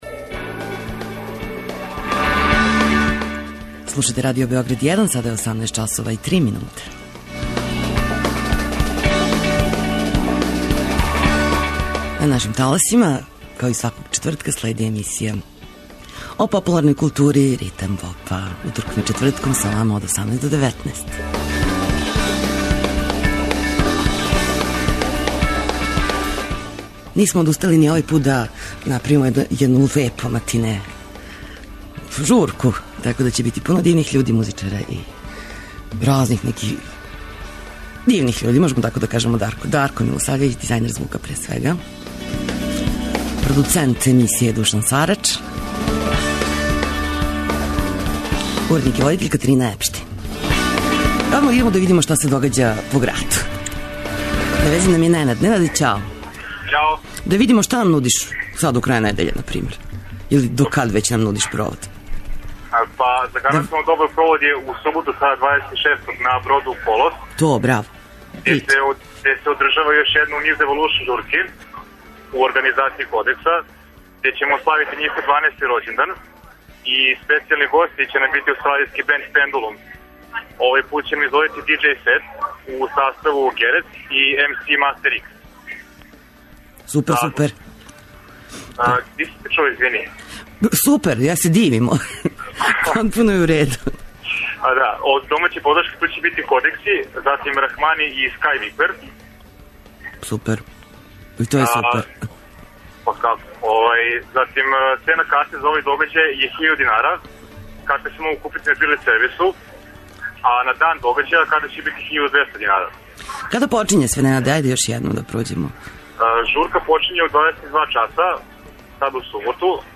Бенд "Клопка за пионира" познат је по необичном изразу заснованом на импровизацији, креативном третману инструмената, примени буке и сарадњи човека и машине. Посебно важну улогу у њиховом звуку имају ангажовани текстови интерпретирани на експресиван начин.